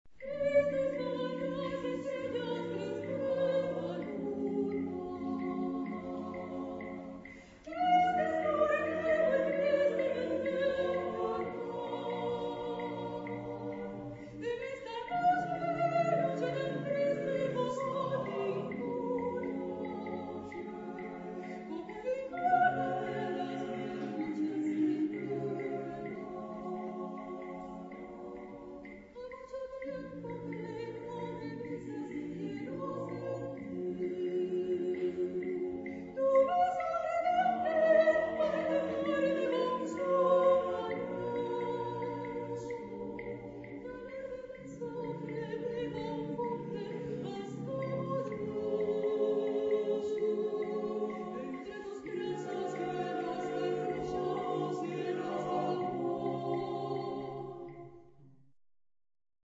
... Bolero ...
Género/Estilo/Forma: Profano ; Popular ; Danza
Tipo de formación coral: SATTBB  (6 voces Coro mixto )
Solistas : Soprano (1)  (1 solista(s) )
Tonalidad : mi menor